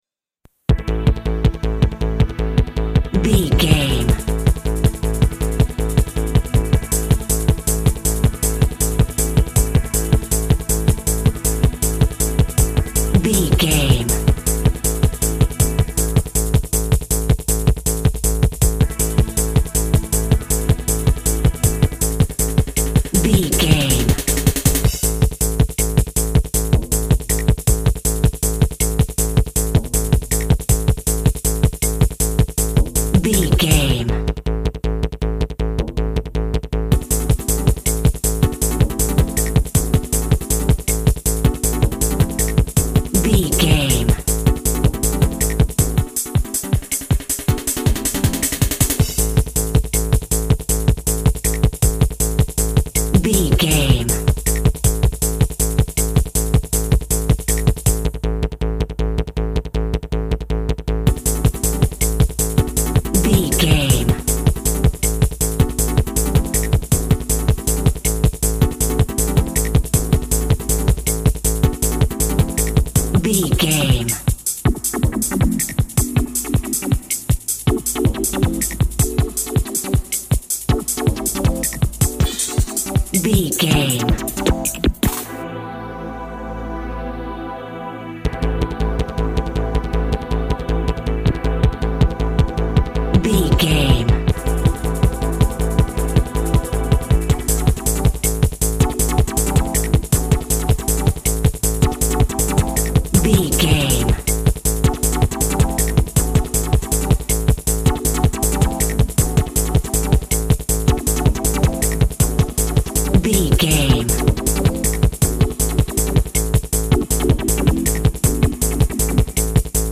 Promo Trance Music.
Ionian/Major
Fast
driving
energetic
futuristic
hypnotic
frantic
drum machine
techno
dance instrumentals
synth lead
synth bass
Electronic drums
Synth pads